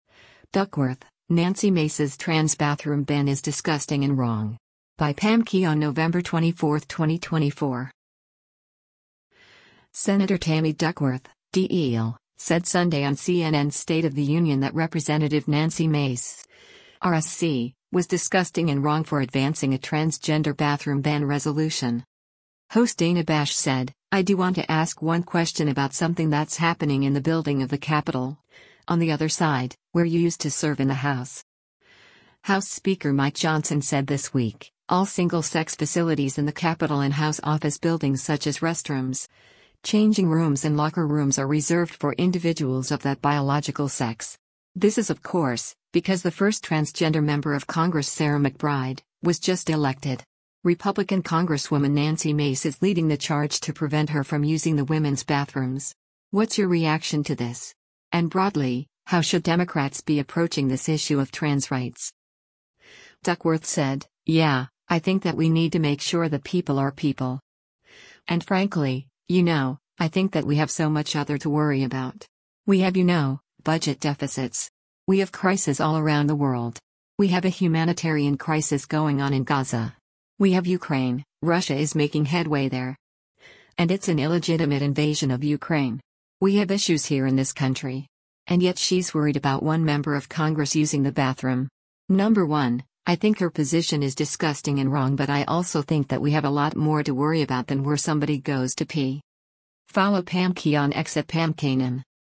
Senator Tammy Duckworth (D-IL) said Sunday on CNN’s “State of the Union” that Rep. Nancy Mace (R-SC) was “disgusting and wrong ” for advancing a transgender bathroom ban resolution.